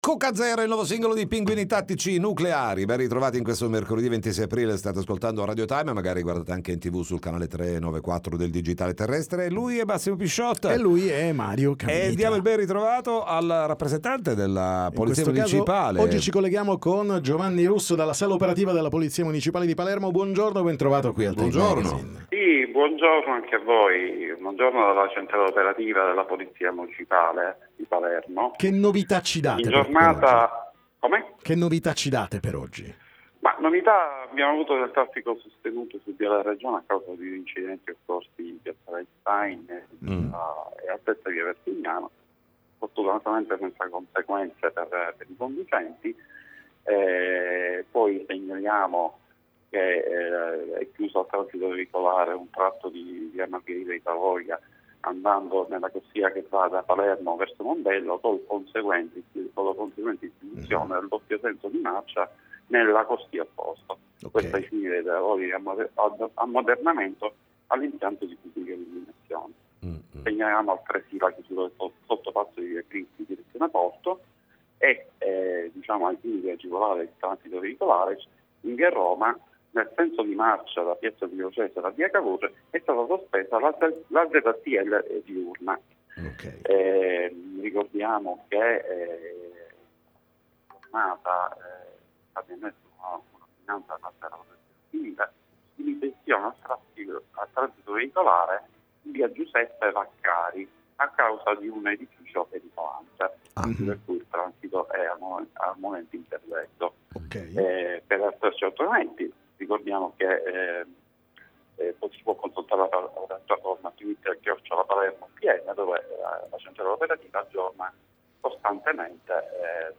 TM Intervista Polizia Municipale